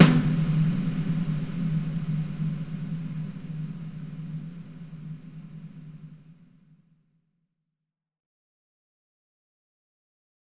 Big Drum Hit 08.wav